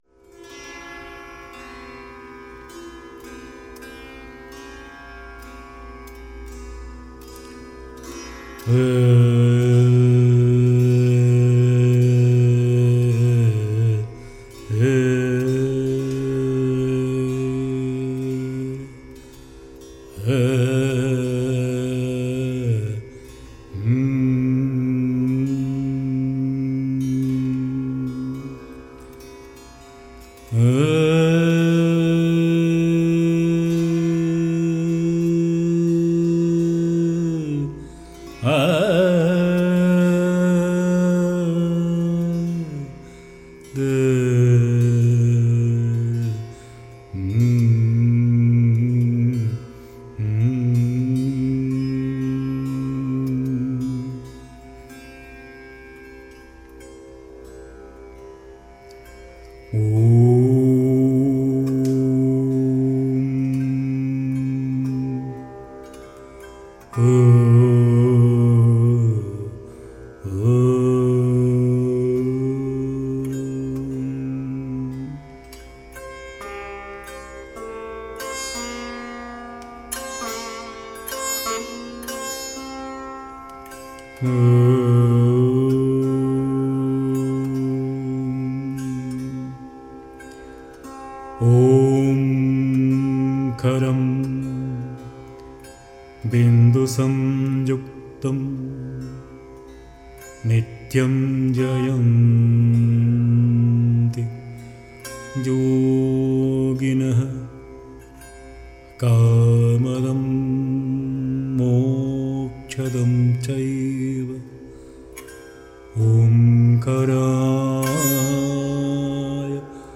mantras
con el sitar